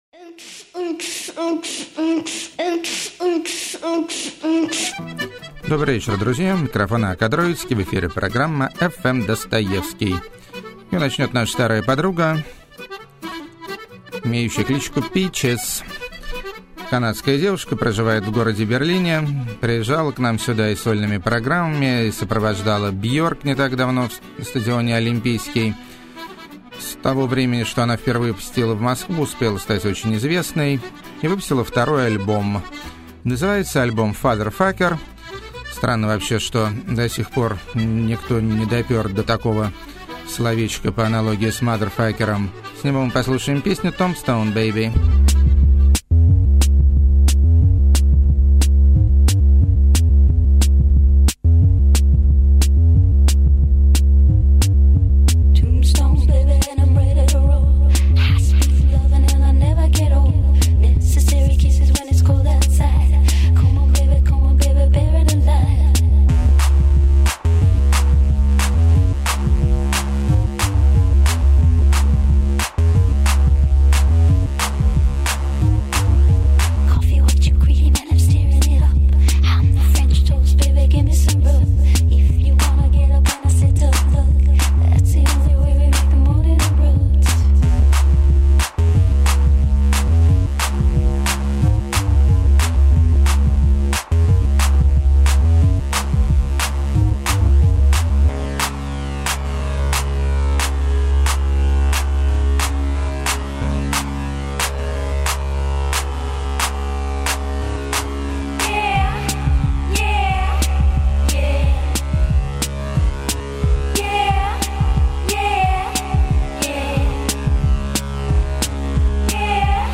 No-vaseline Rockabilly
Classic Italian Canzona With Electronic Mutation
Radical Hip Hop Indeed
Less Electro, More Punk